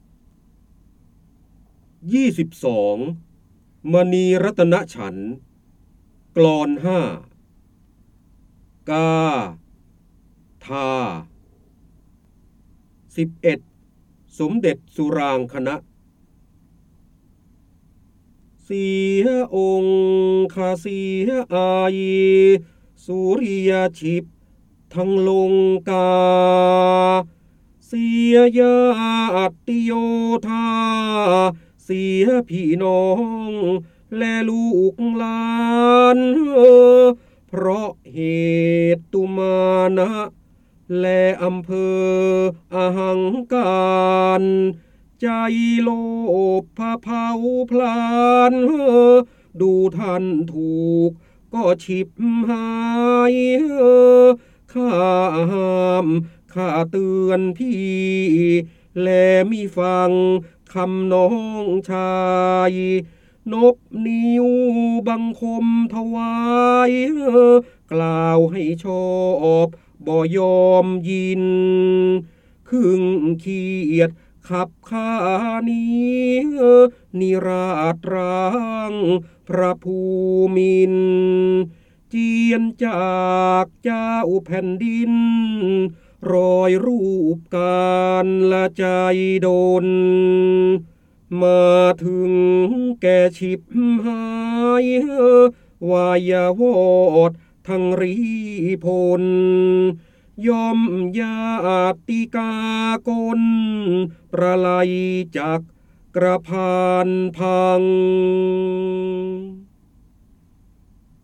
เสียงบรรยายจากหนังสือ จินดามณี (พระโหราธิบดี) ๒๒ มณีรัตนฉันท กลอน ๕ ฯ
ลักษณะของสื่อ :   คลิปการเรียนรู้, คลิปเสียง